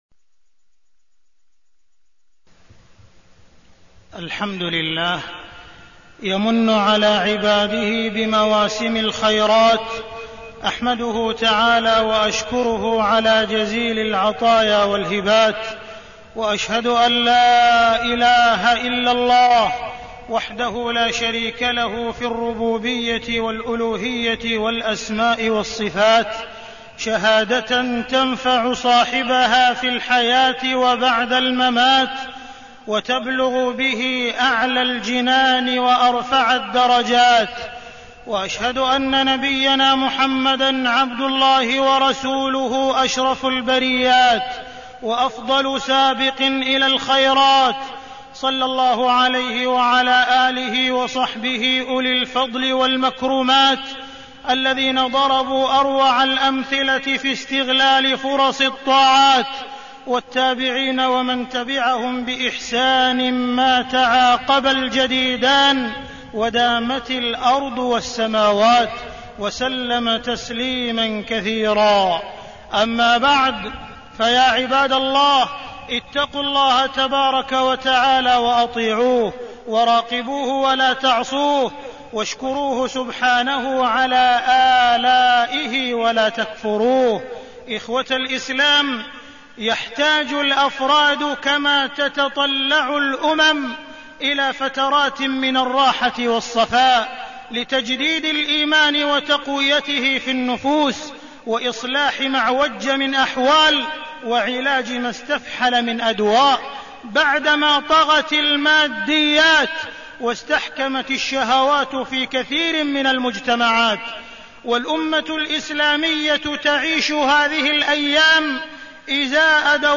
تاريخ النشر ٢٩ شعبان ١٤١٩ هـ المكان: المسجد الحرام الشيخ: معالي الشيخ أ.د. عبدالرحمن بن عبدالعزيز السديس معالي الشيخ أ.د. عبدالرحمن بن عبدالعزيز السديس تجديد الإيمان في رمضان